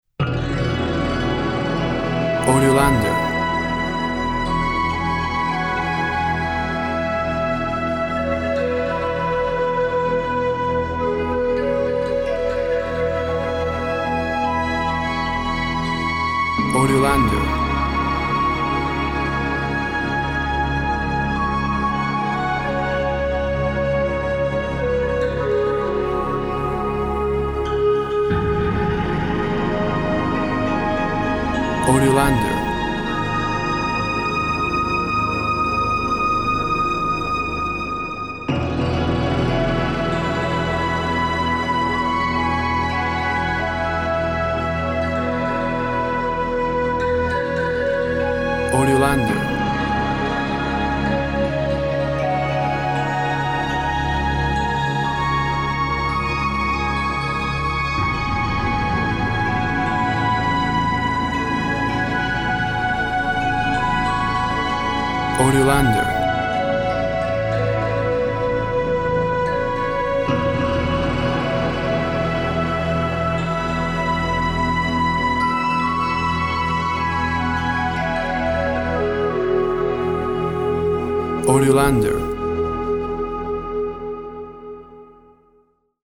Burst of energy as the sun rises over the horizon.
Tempo (BPM) 55